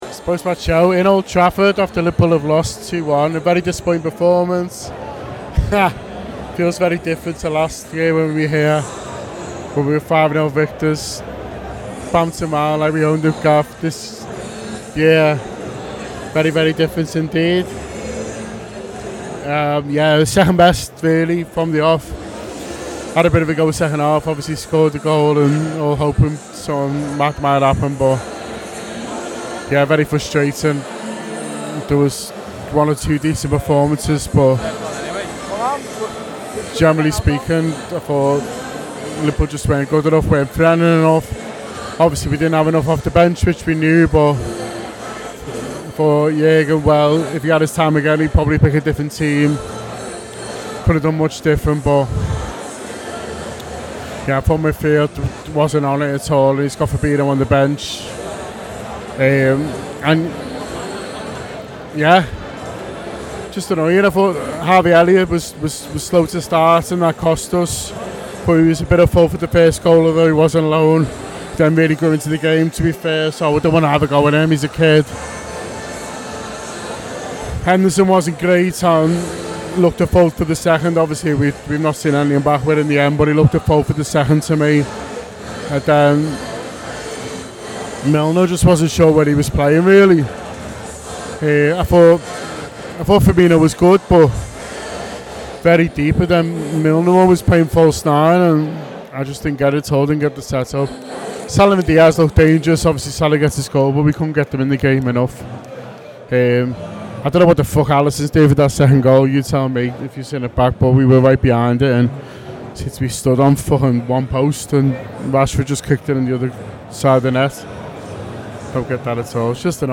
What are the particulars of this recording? The Anfield Wrap’s post-match reaction podcast after Manchester United 2 Liverpool 1 in the Premier League at Old Trafford.